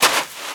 HauntedBloodlines/STEPS Sand, Walk 17.wav at main
STEPS Sand, Walk 17.wav